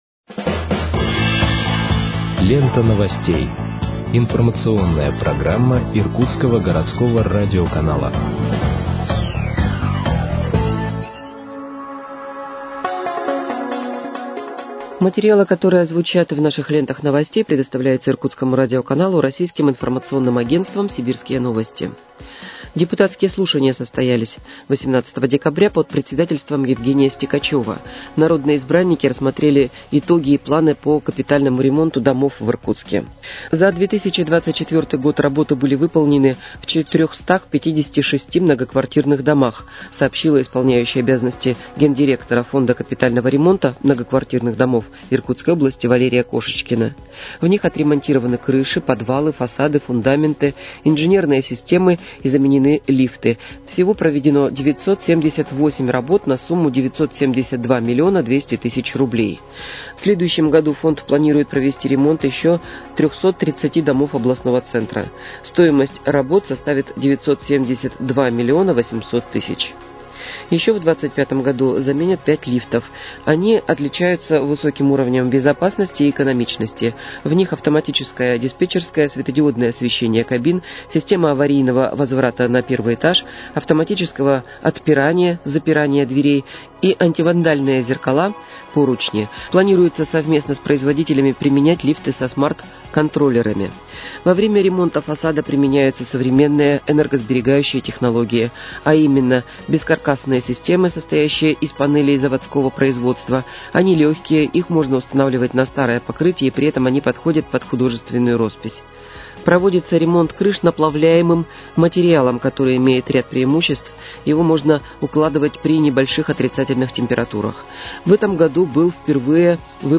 Выпуск новостей в подкастах газеты «Иркутск» от 23.12.2024 № 2